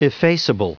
Prononciation du mot effaceable en anglais (fichier audio)
Prononciation du mot : effaceable